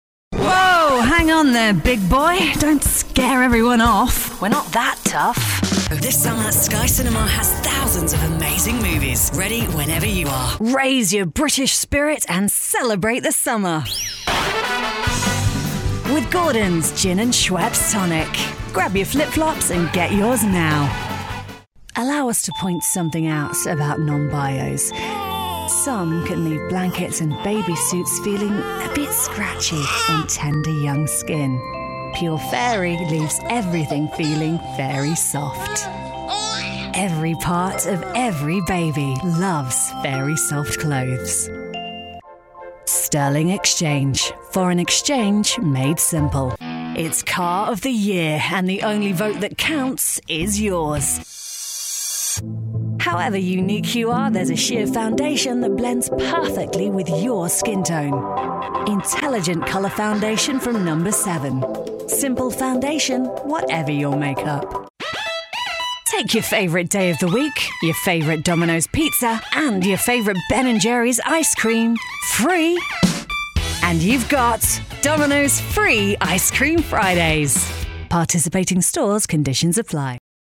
• Female
• Cockney
• Essex
• London